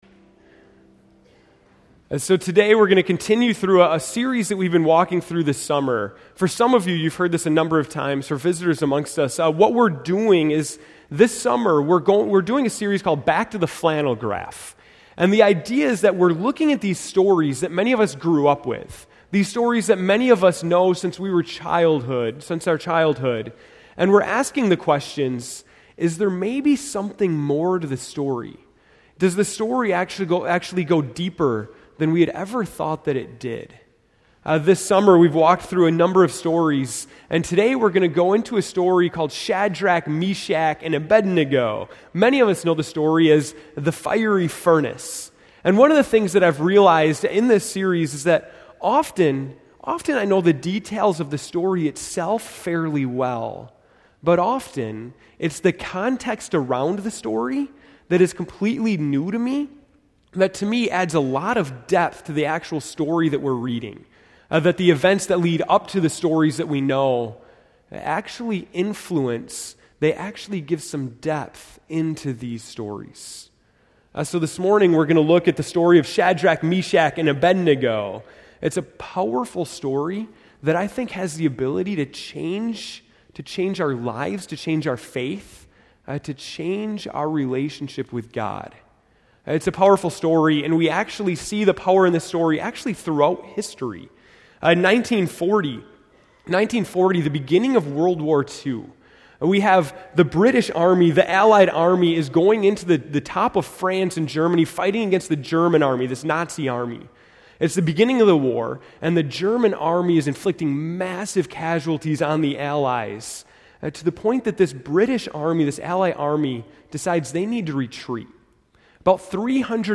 August 18, 2013 (Morning Worship)